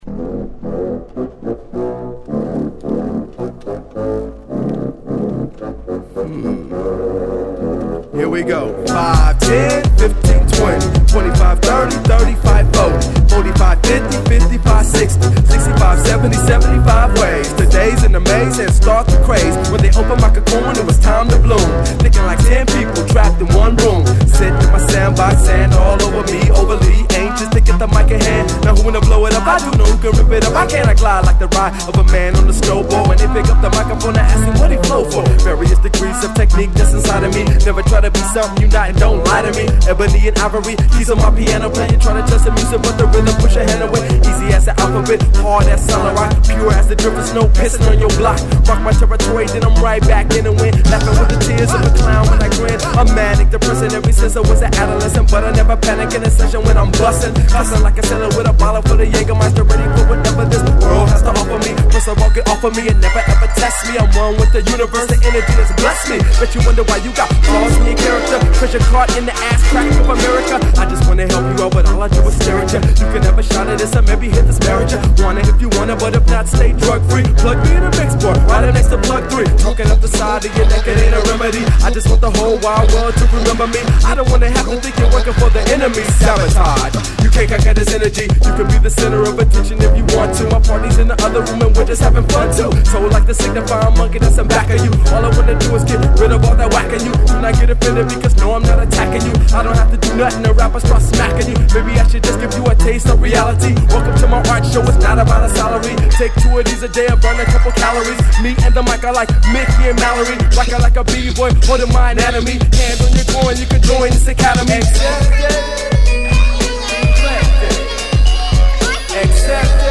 ホーム HIP HOP UNDERGROUND 12' & LP A